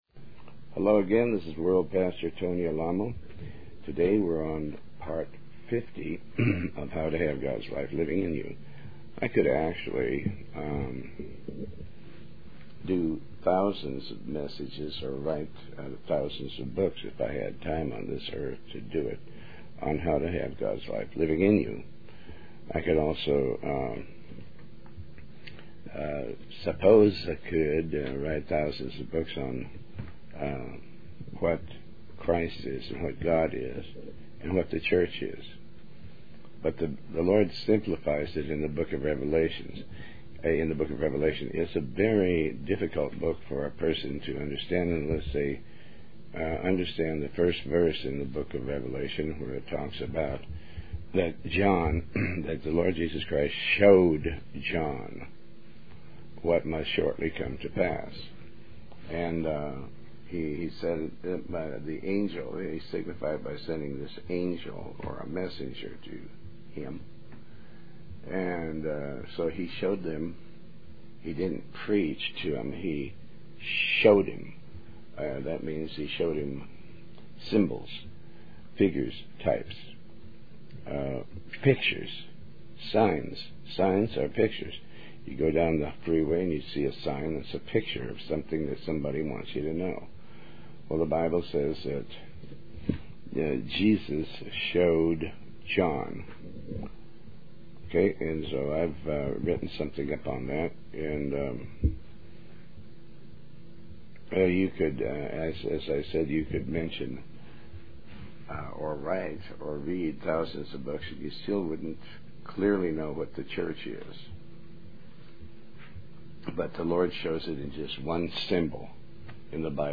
Talk Show Episode, Audio Podcast, Tony Alamo and Ep152, How To Have Gods Life Living In You, Part 50 on , show guests , about How To Have Gods Life Living In You, categorized as Health & Lifestyle,History,Love & Relationships,Philosophy,Psychology,Christianity,Inspirational,Motivational,Society and Culture